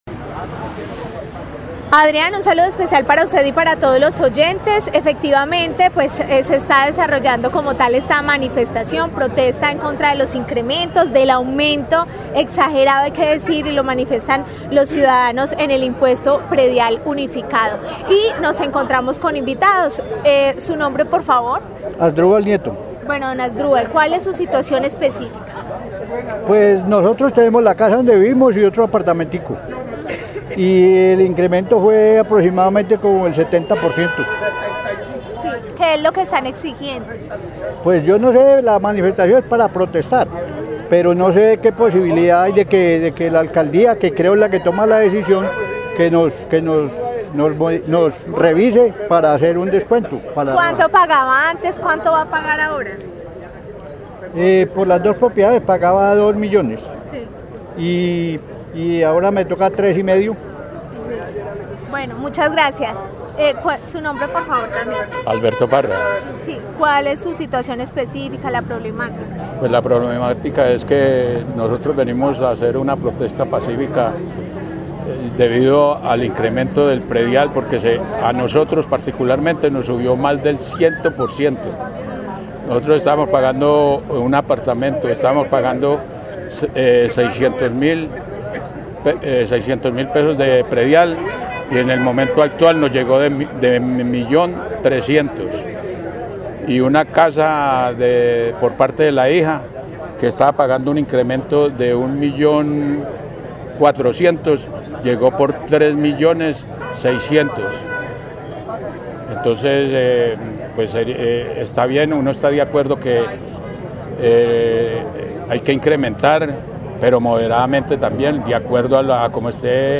Informe marcha incremento del predial